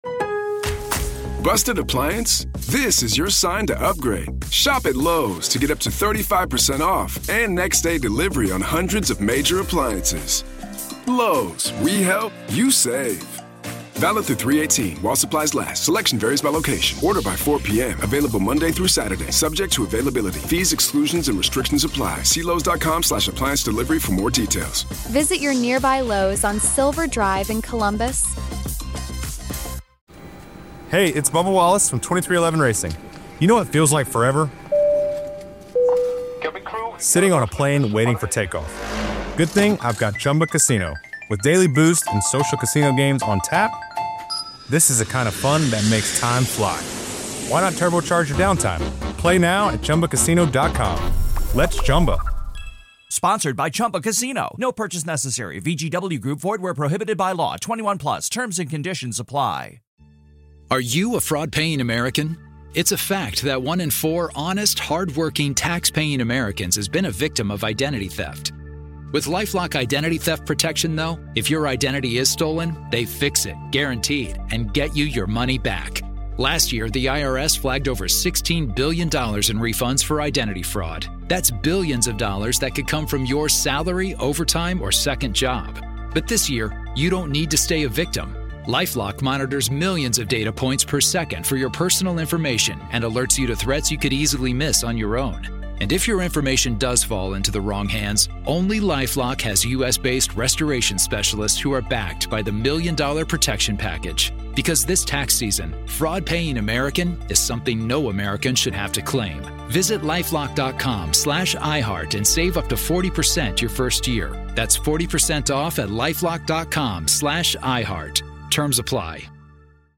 In this classic episode of The Grave Talks , we begin part one of an in-depth conversation